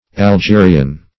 Algerian \Al*ge"ri*an\, a.